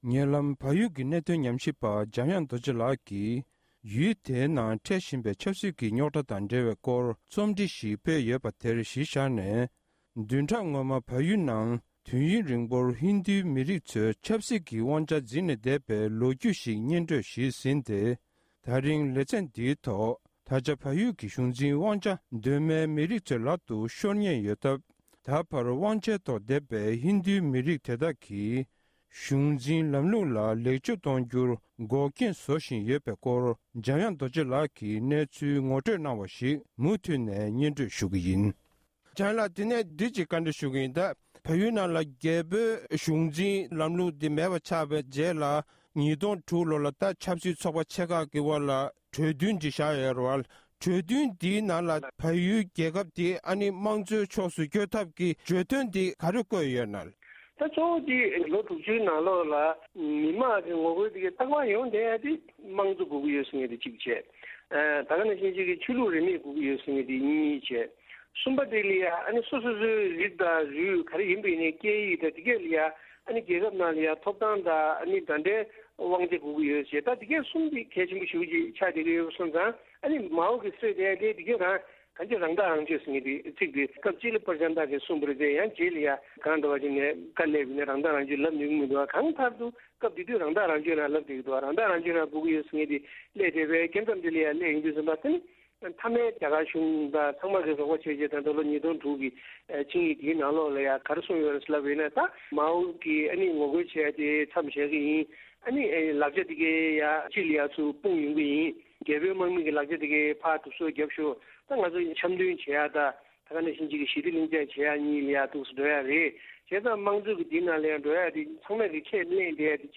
གནས་འདྲི་ཞུས་པར་མུ་མཐུད་ནས་གསན་རོགས༎